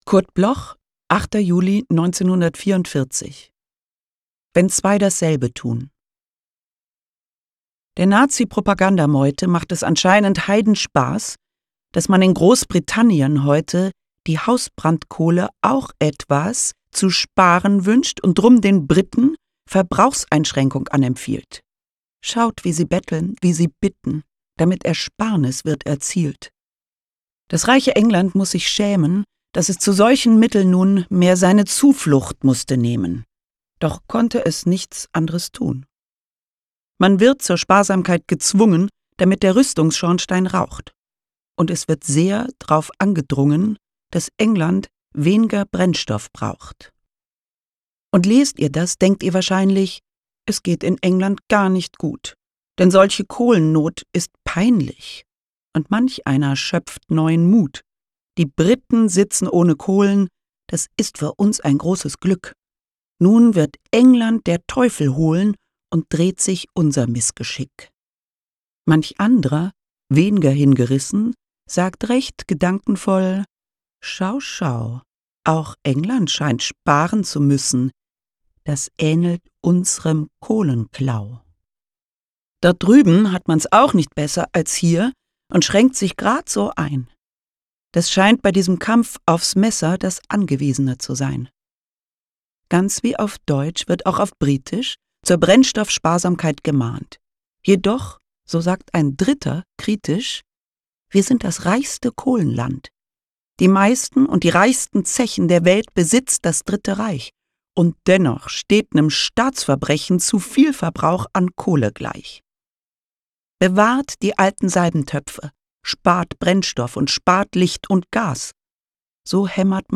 Recording and Editing: Kristen & Schmidt, Wiesbaden
Caroline Peters (* 1971) is een Duitse toneelspeelster en hoorspelactrice.